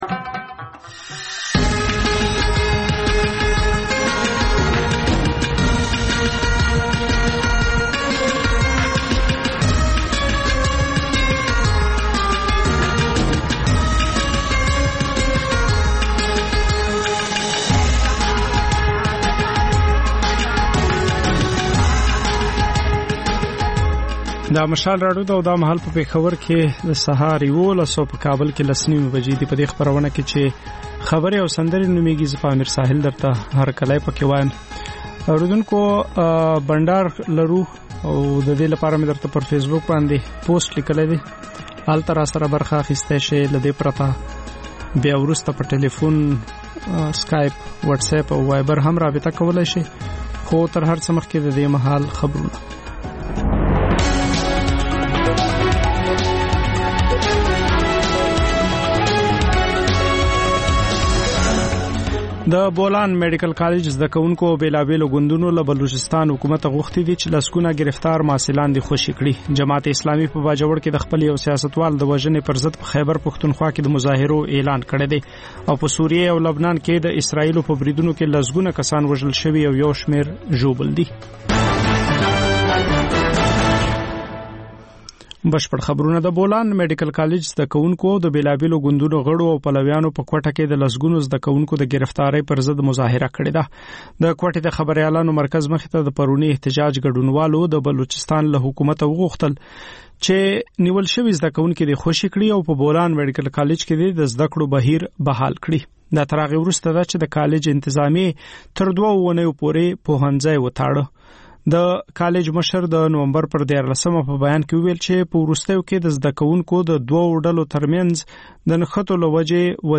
په دې خپرونه کې تر خبرونو وروسته له اورېدونکو سره په ژوندۍ بڼه خبرې کېږي، د هغوی پیغامونه خپرېږي او د هغوی د سندرو فرمایشونه پوره کول کېږي.